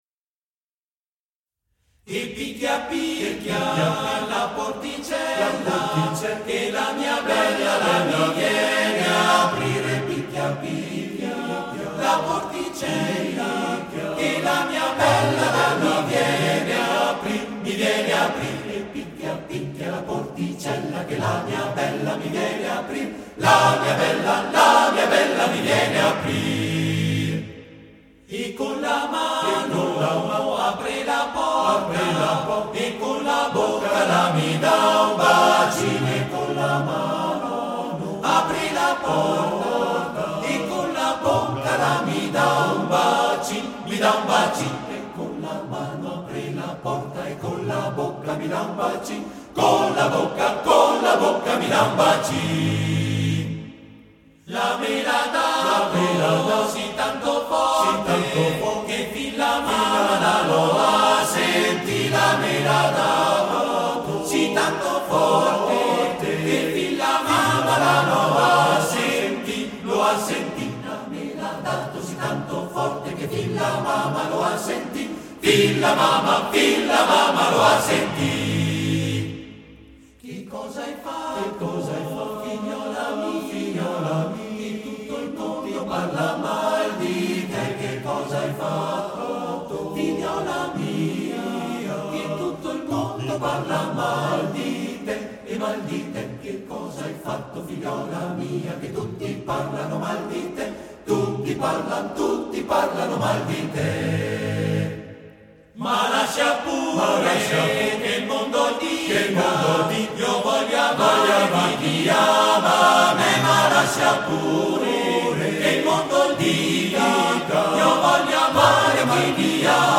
Esecutore: Coro della SAT